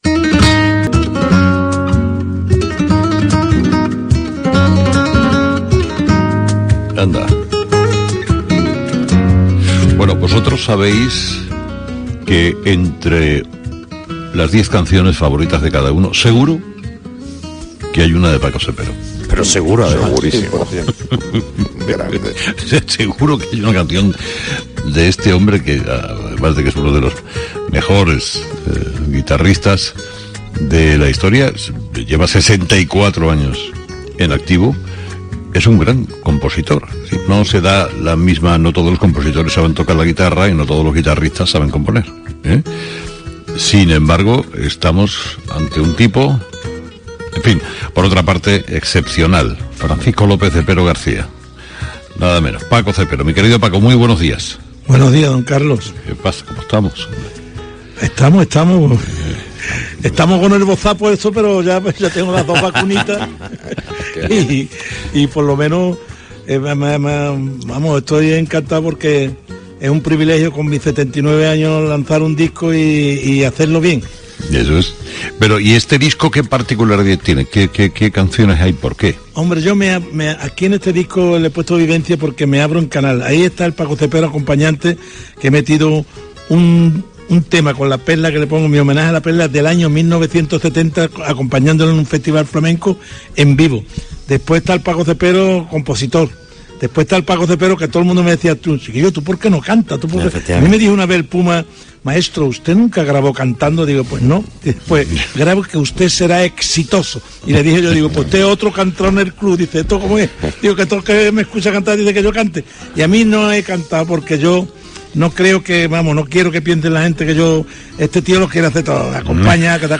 El compositor y guitarrista jerezano comparte con Carlos Herrera y su equipo unos minutos de radio deliciosos y muy ocurrentes
Paco Cepero, en directo en 'Herrera en COPE' desde los estudios de Jerez